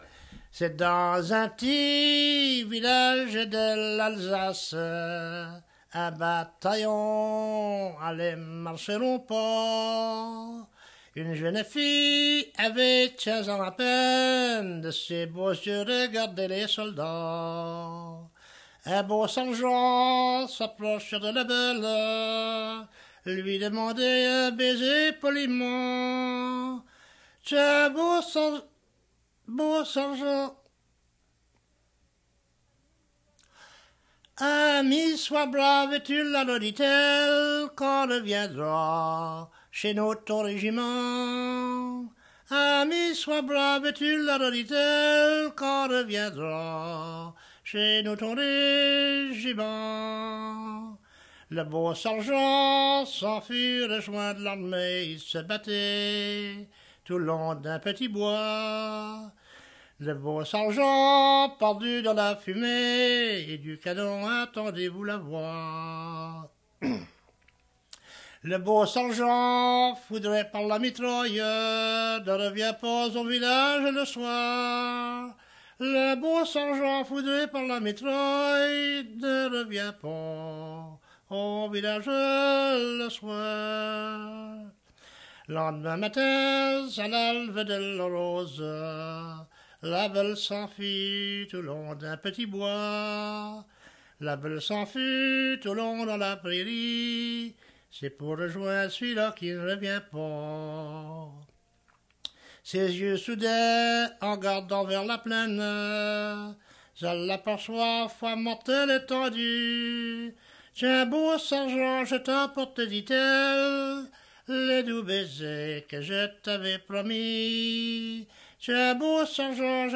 Chanson Item Type Metadata